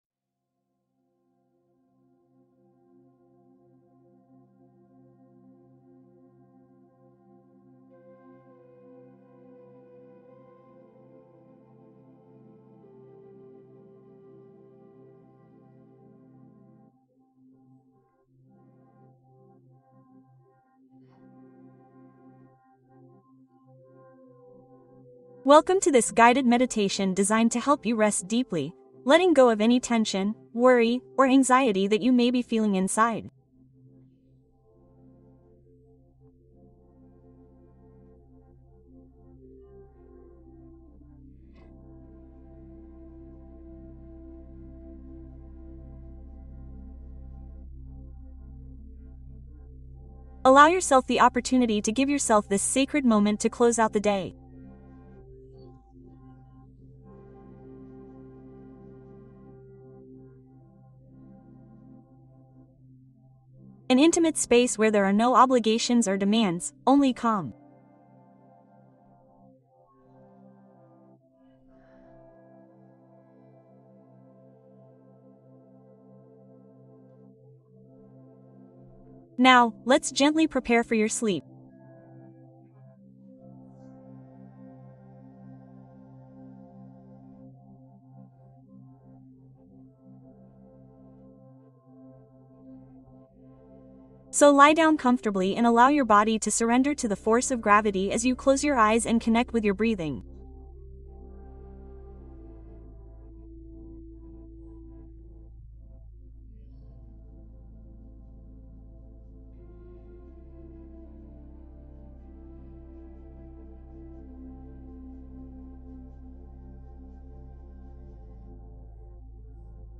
Puerta al descanso: una meditación para soltar actividad mental y rendirte a la noche